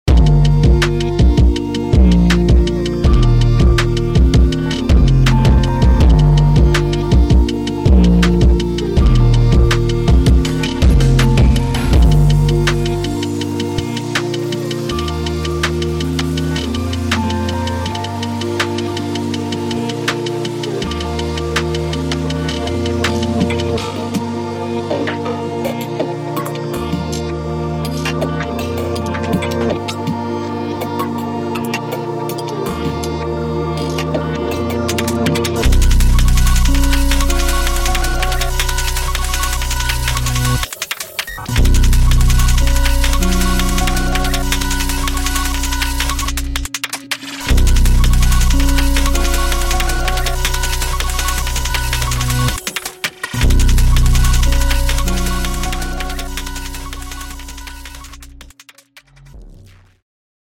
Hyperpop